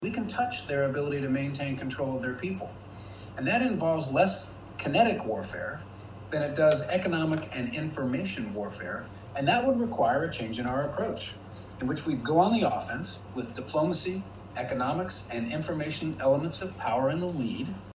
退役海军上将温尼菲尔德说美国可以点穴北京当局的弱点与中国竞争